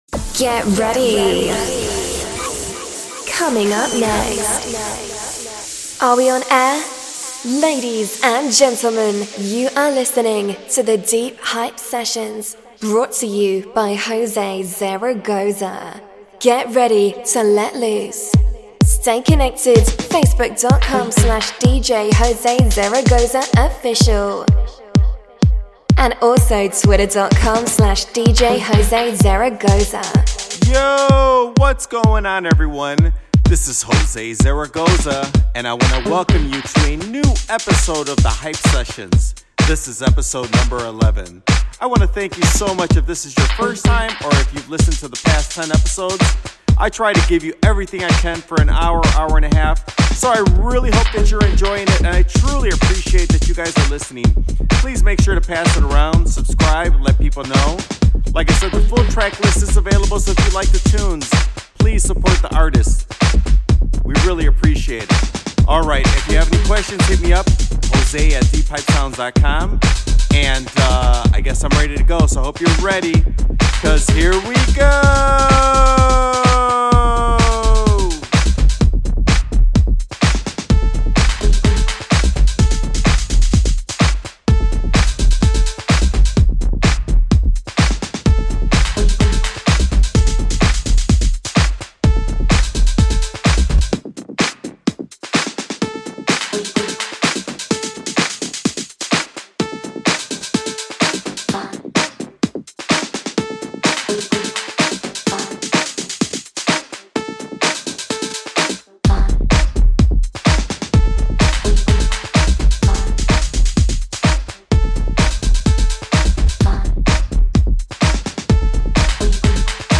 The latest show is out with a cool house vibe and fun.